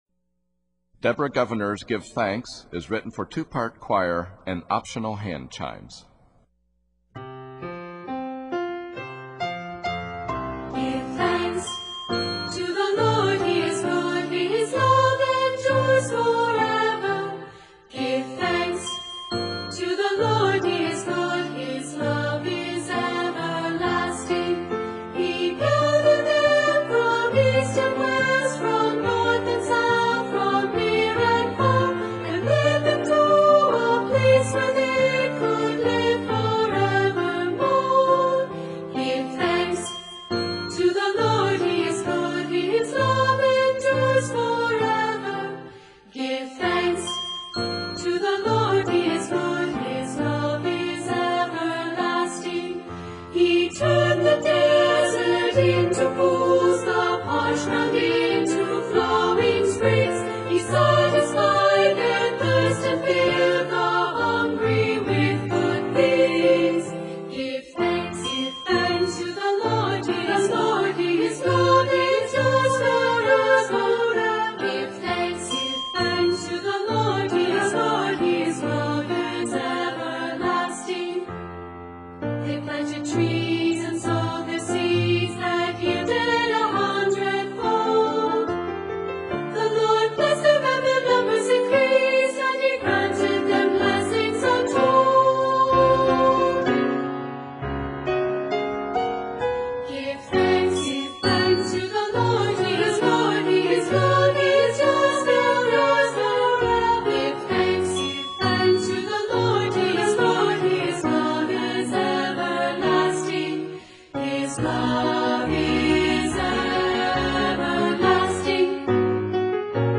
Four handchimes